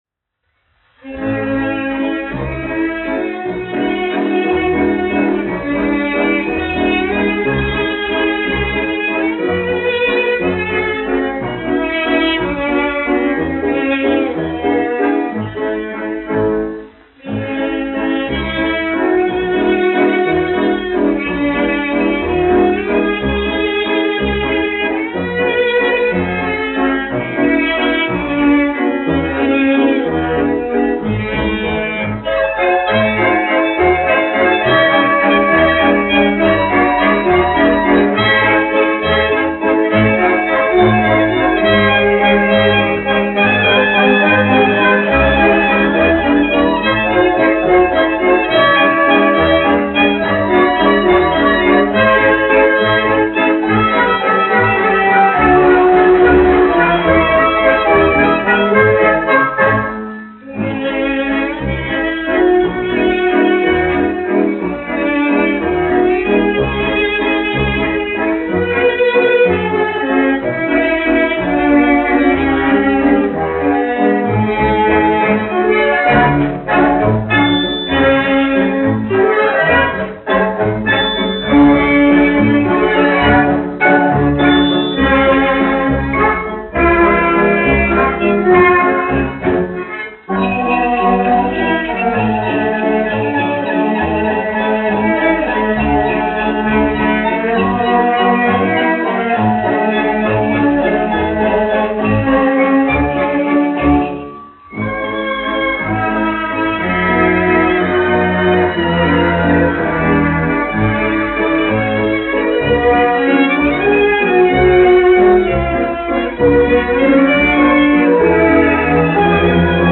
1 skpl. : analogs, 78 apgr/min, mono ; 25 cm
Valši
Populārā instrumentālā mūzika
Skaņuplate